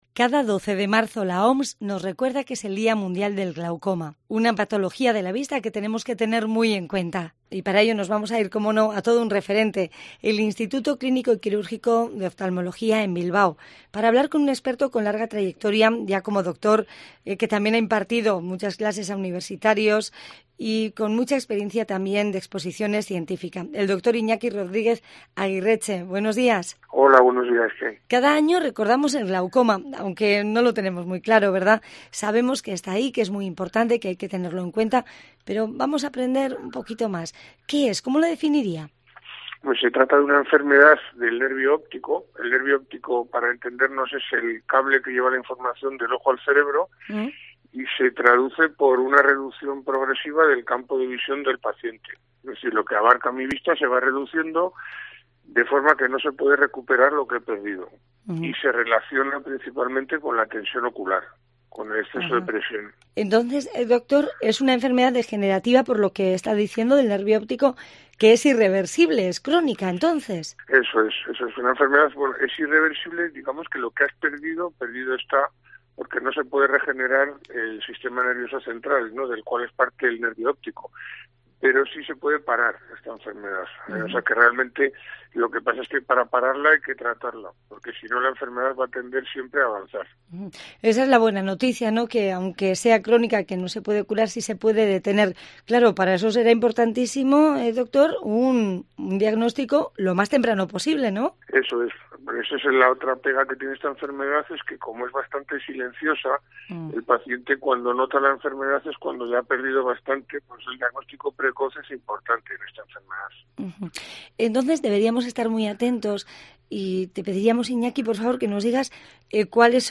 Puedes escuchar en la entrevista cuáles son los síntomas y también las personas que deberían estar más pendientes, aunque todos deberíamos acudir a revisiones para controlar nuestra salud ocular.